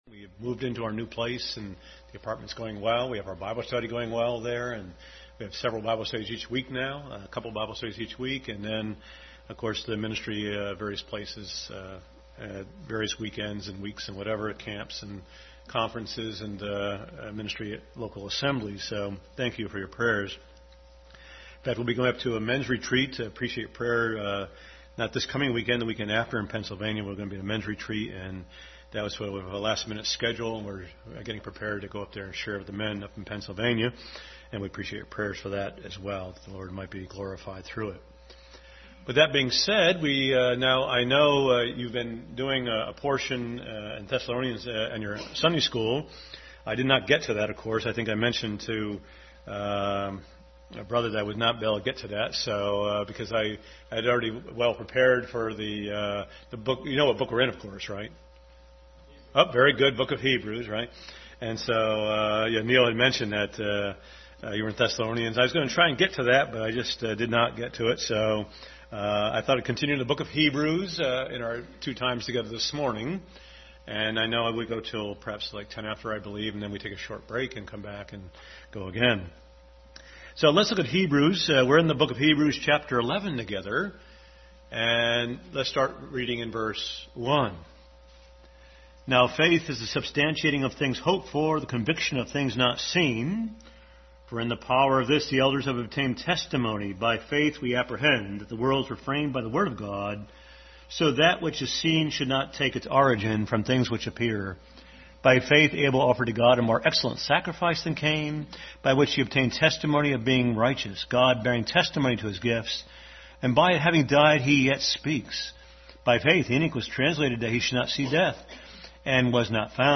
Bible Text: Hebrews 11:1-10, Hebrews 10:38-39 | Adult Sunday School. Continued study in the book of Hebrews.